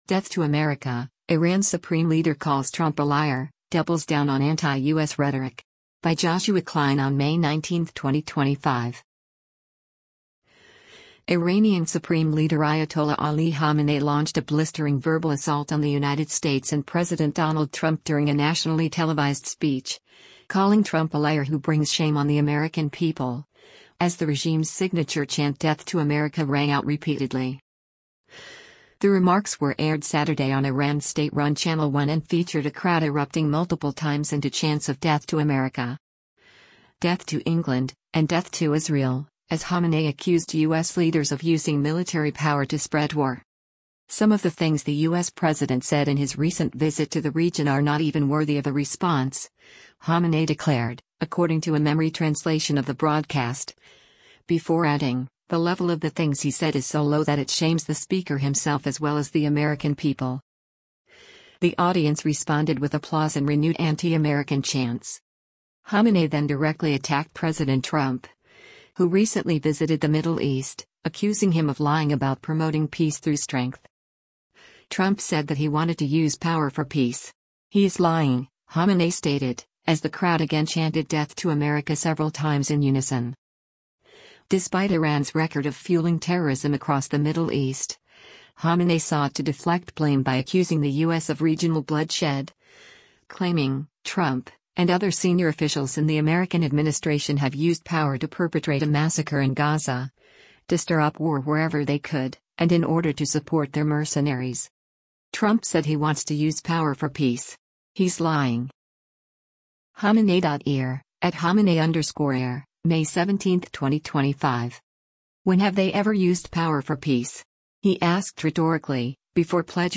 Iranian Supreme Leader Ayatollah Ali Khamenei launched a blistering verbal assault on the United States and President Donald Trump during a nationally televised speech, calling Trump a liar who brings shame on the American people, as the regime’s signature chant “Death to America” rang out repeatedly.
The remarks were aired Saturday on Iran’s state-run Channel 1 and featured a crowd erupting multiple times into chants of “Death to America,” “Death to England,” and “Death to Israel,” as Khamenei accused U.S. leaders of using military power to spread war.
The audience responded with applause and renewed anti-American chants.
“Trump said that he wanted to use power for peace. He is lying,” Khamenei stated, as the crowd again chanted “Death to America” several times in unison.